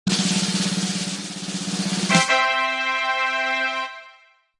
Звуки тадам
Звук триумфа на сцене